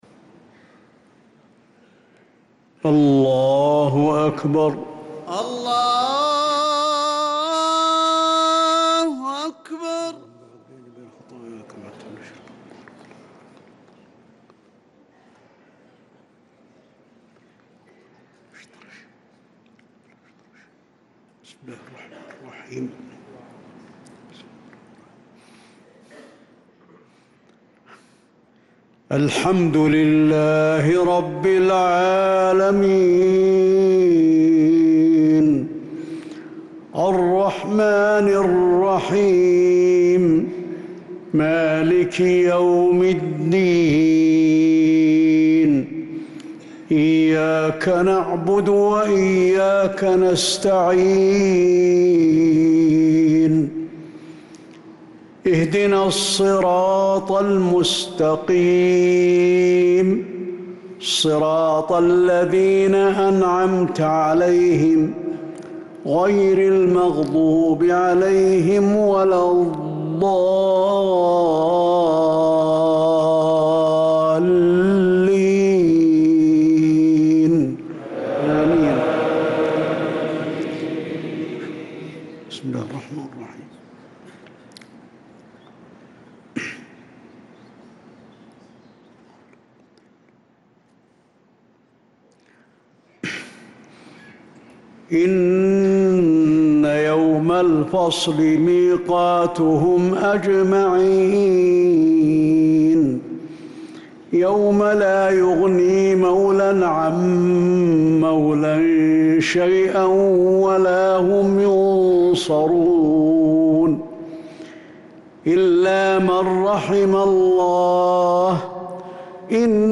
صلاة المغرب للقارئ علي الحذيفي 16 ربيع الآخر 1446 هـ
تِلَاوَات الْحَرَمَيْن .